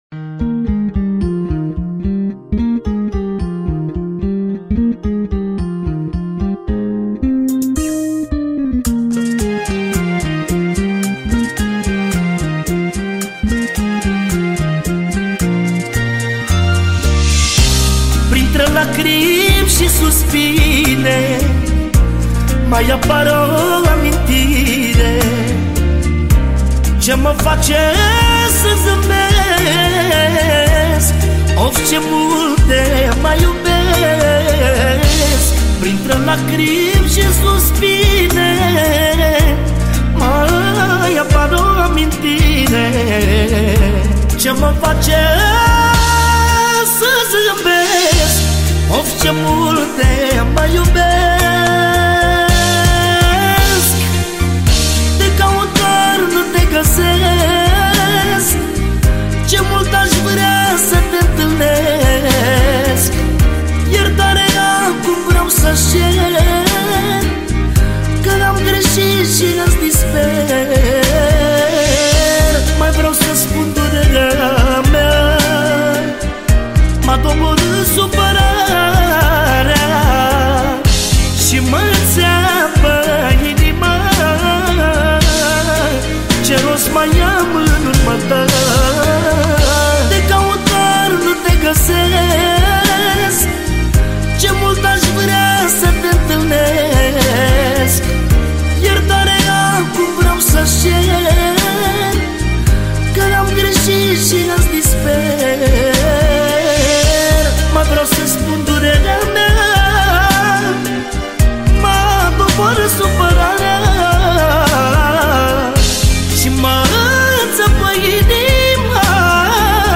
Data: 31.10.2024  Manele New-Live Hits: 0